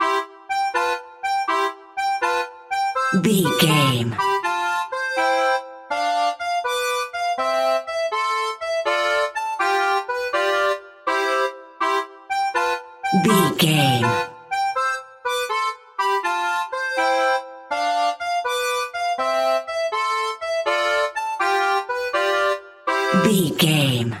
Ionian/Major
Slow
nursery rhymes
kids music